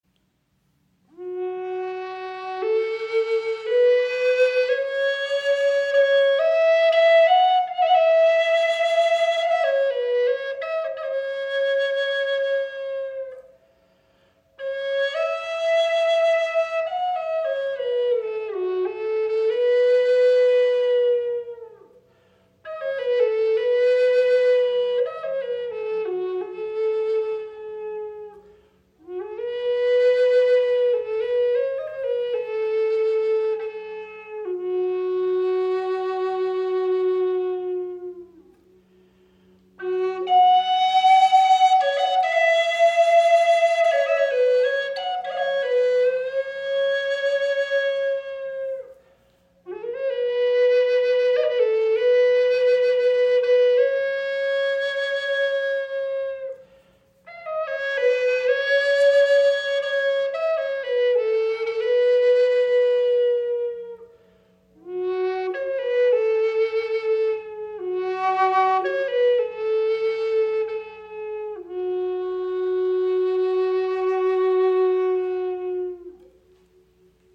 Ideal für entspannte Klangmomente zu Hause, unterwegs in der Natur oder meditativen Übungen.
Unsere Golden Eagle Flöte in Fis-Moll gibt es in drei besonderen Holzarten: Aromatic Cedar mit warmem, vollem Klang und schönen Rot- bis Orangetönen, Spanish Cedar mit klarer, resonanter Stimme und sanften Höhen sowie Walnut, einem robusten Hartholz mit hellen, klaren Tönen – perfekt für den Einsatz unterwegs.
Unsere Golden Eagle Flöte in der Tonart Fis-Moll (440 Hz) ist in drei einzigartigen Holzvarianten erhältlich – jede mit ihrem ganz eigenen Klangcharakter und natürlicher Schönheit.